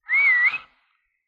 Whistle.wav